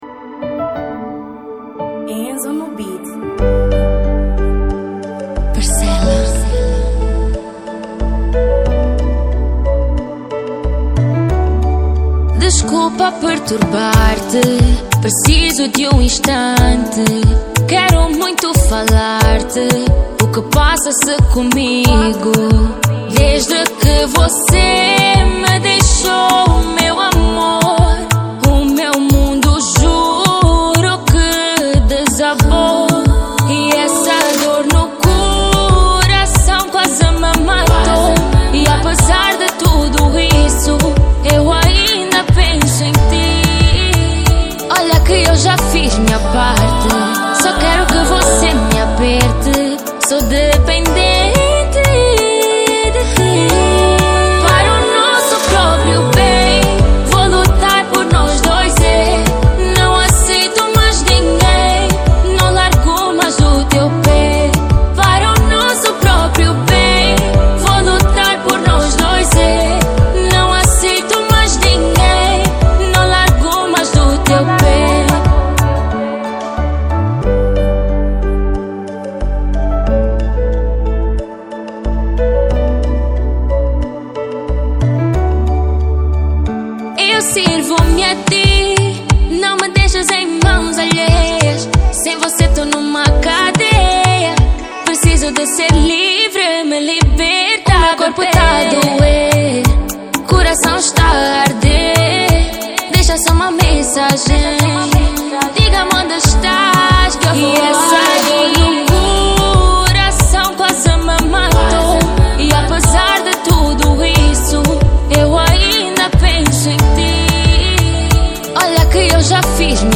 Gênero: Kizomba